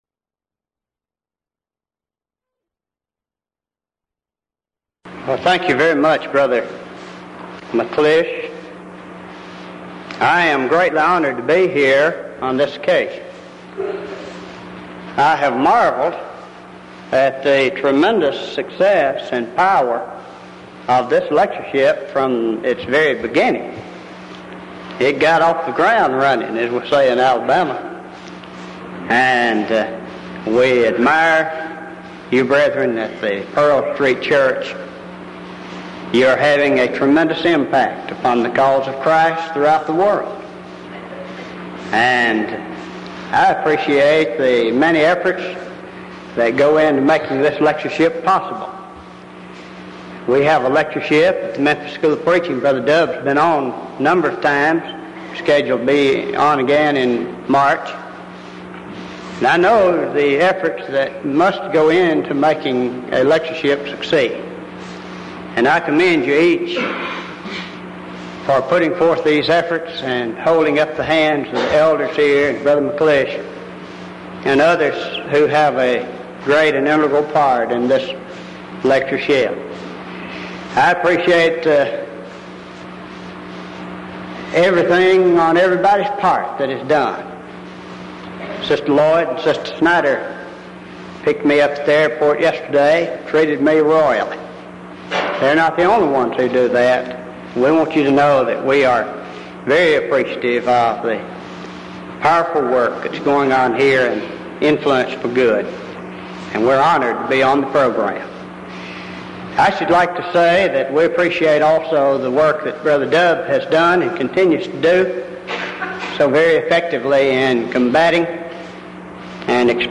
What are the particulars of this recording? Event: 1985 Denton Lectures